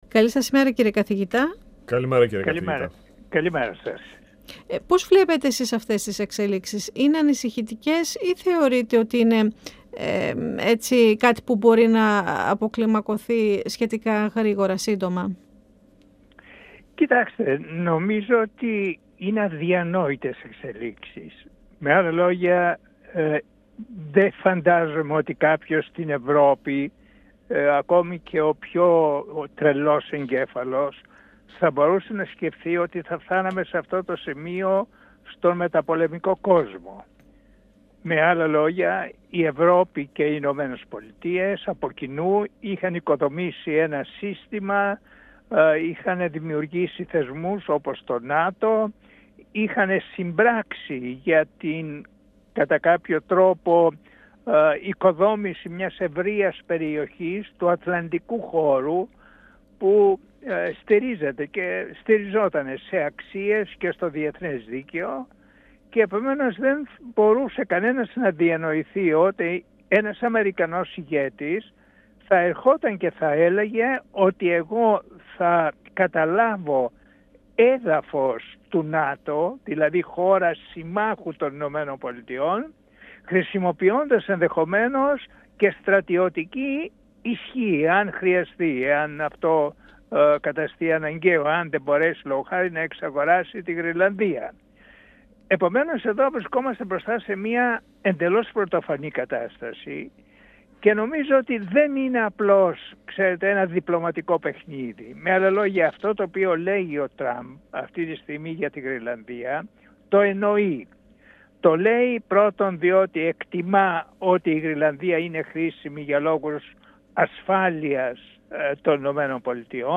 Από την άλλη, πρόσθεσε, δεν μπορεί παρά να ταχθεί υπέρ των κανόνων διεθνούς δικαίου και στο πλευρό μιας χώρας- μέλους της ΕΕ και του ΝΑΤΟ που είναι η Δανία. 102FM Ο Μεν και η Δε Συνεντεύξεις ΕΡΤ3